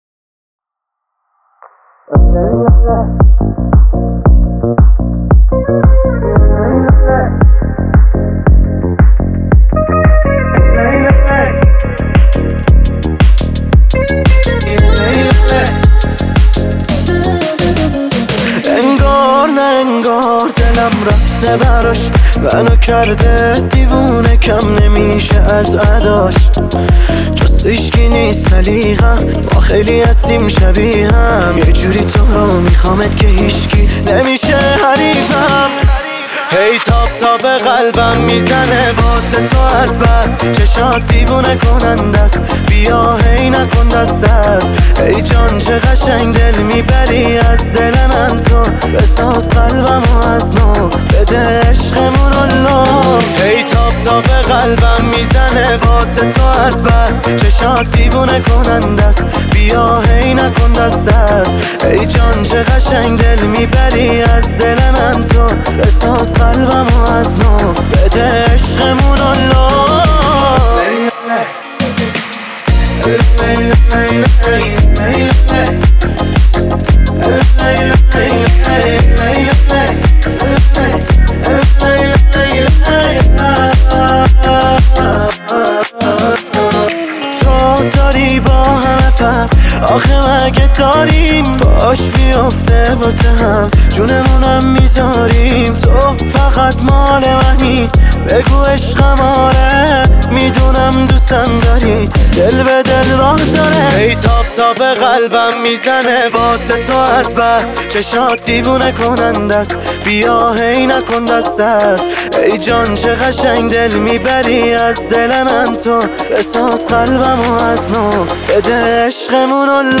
پاپ
آهنگ با صدای زن
اهنگ ایرانی